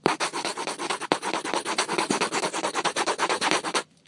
铅笔涂鸦
描述：学生在一张纸上涂鸦的声音。
标签： 绘图 涂鸦 学校 铅笔 教室
声道立体声